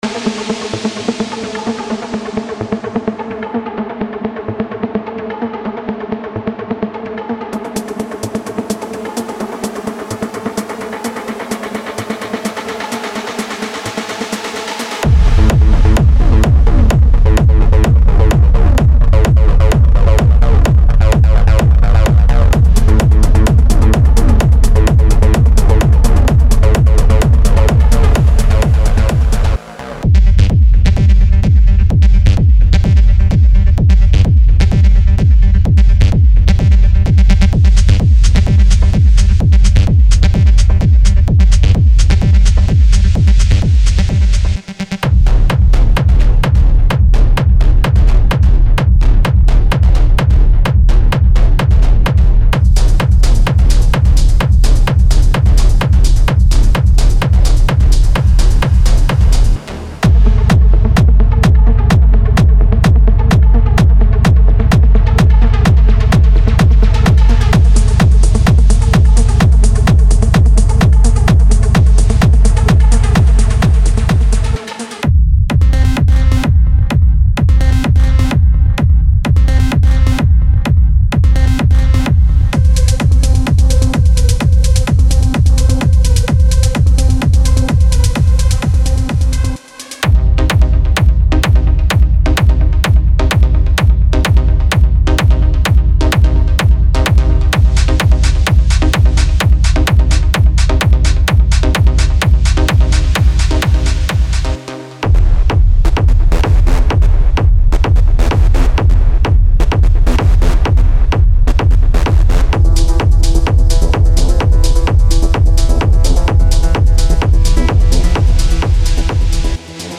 Genre:Industrial Techno
デモサウンドはコチラ↓
Tempo/Bpm 128-135